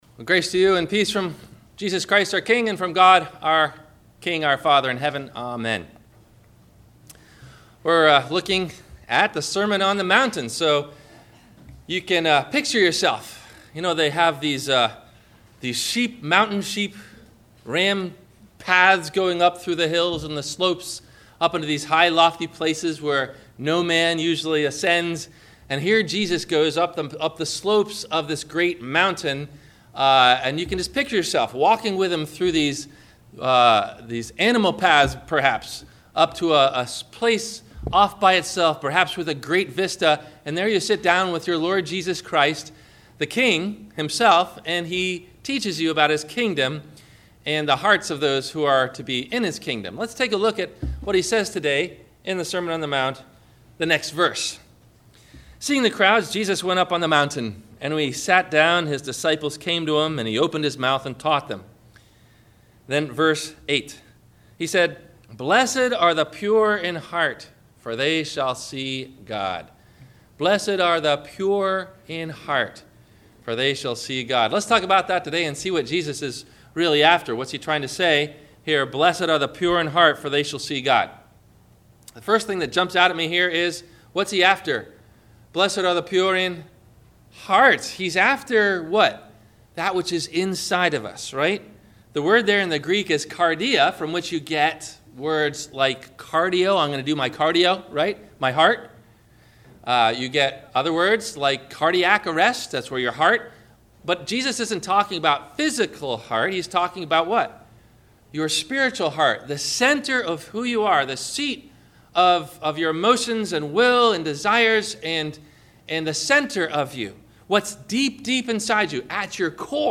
Questions to think about before you hear the Sermon: